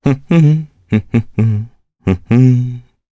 Crow-Vox_Hum_jp.wav